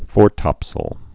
(fôrtŏpsəl, -təp-)